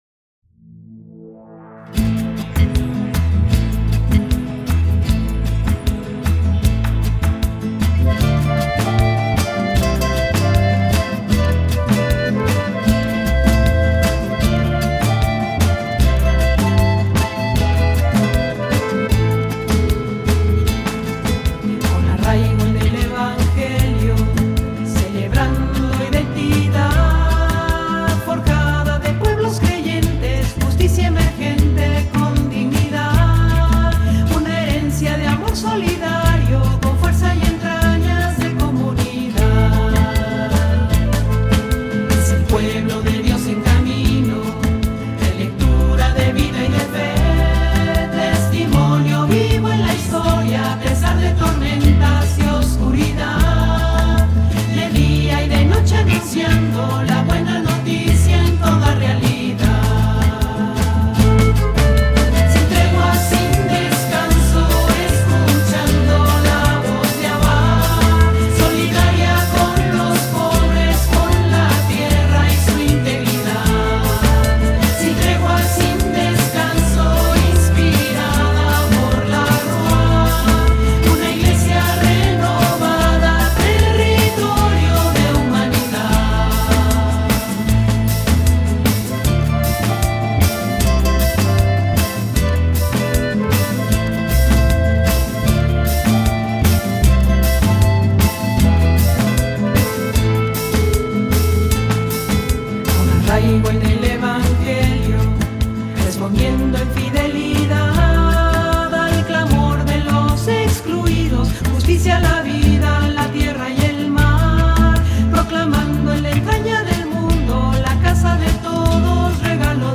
Himno